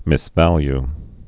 (mĭs-văly)